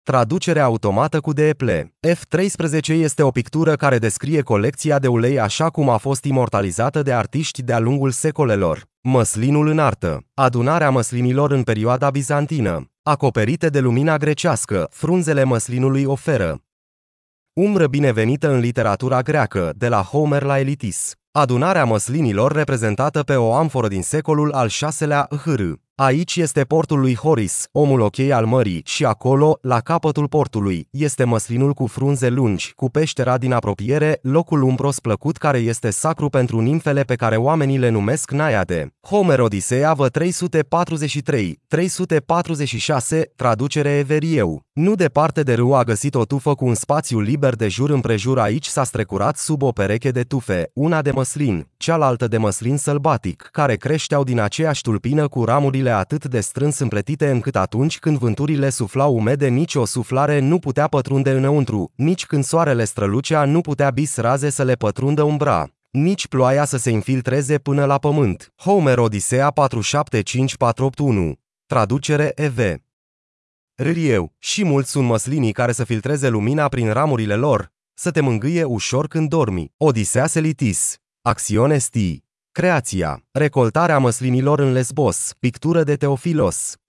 Tur ghidat audio